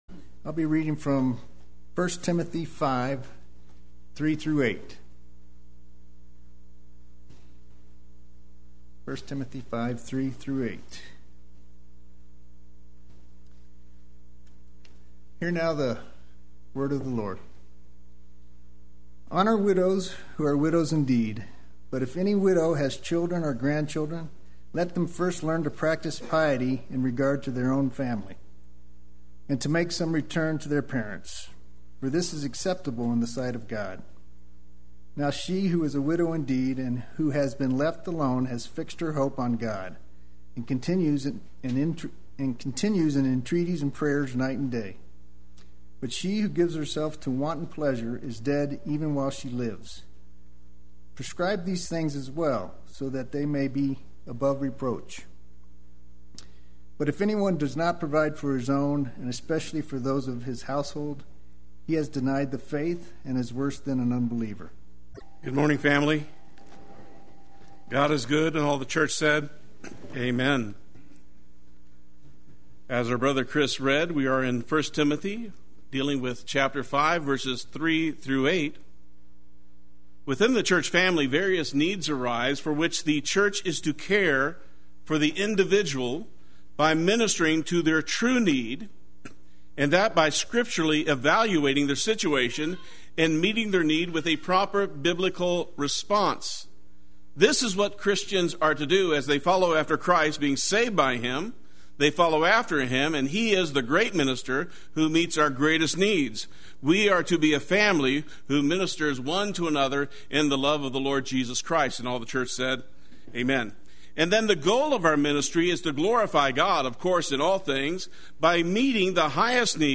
Play Sermon Get HCF Teaching Automatically.
Honor Widows Sunday Worship